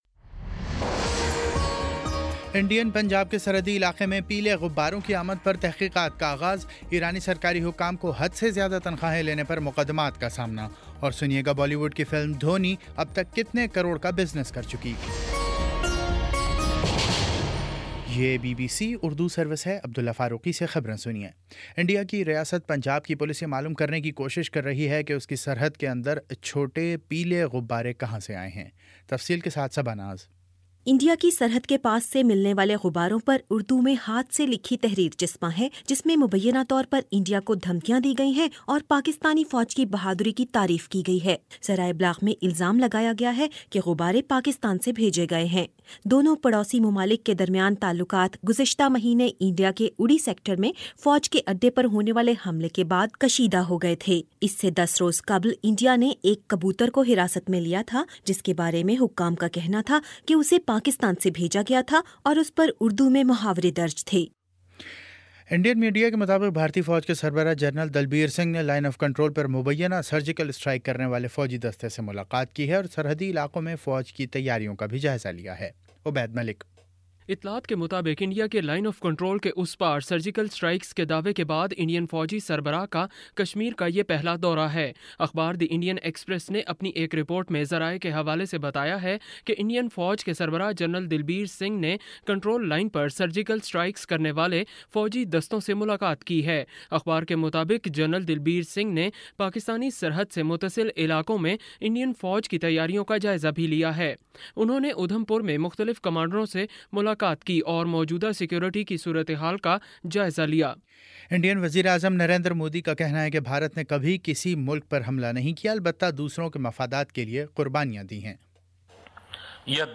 اکتوبر02 : شام سات بجے کا نیوز بُلیٹن